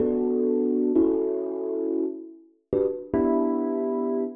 35 ElPiano PT1-4.wav